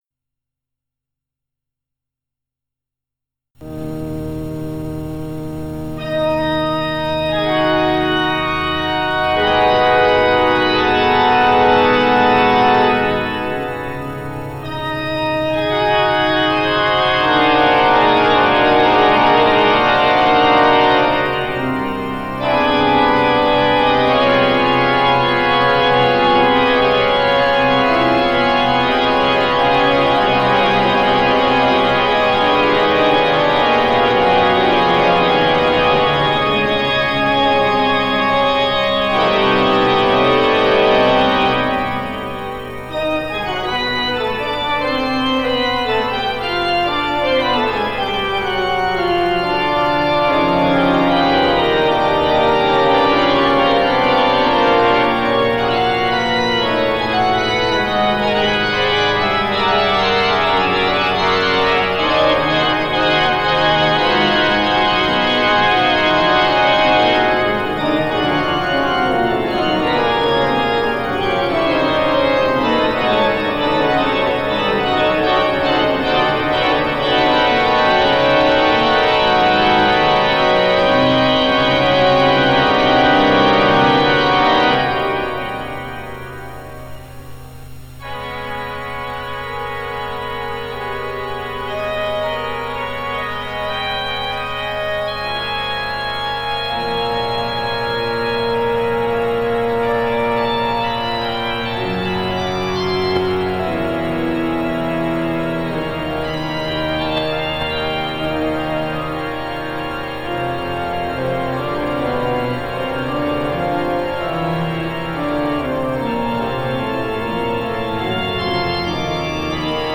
Improvisation �ber ein Thema von Daniel Roth an der Steinmeyer-Orgel der evang. Stadtkirche Kitzingen